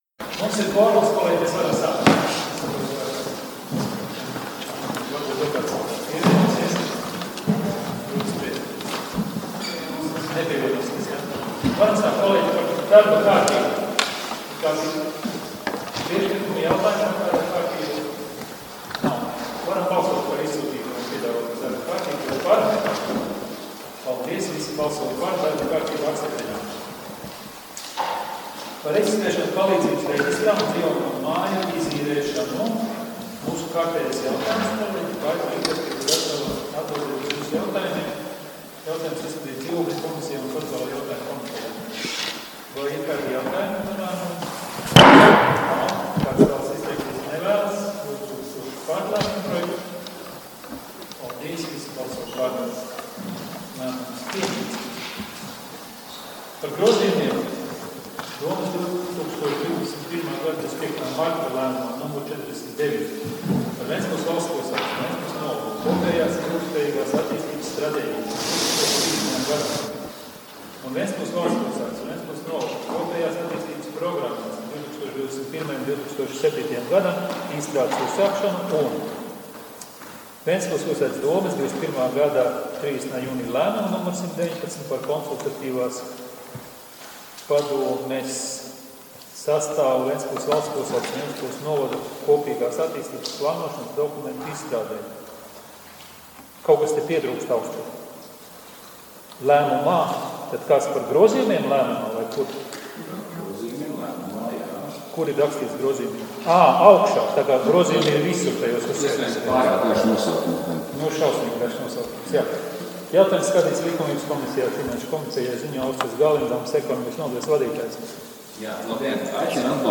Domes sēdes 03.04.2020. audioieraksts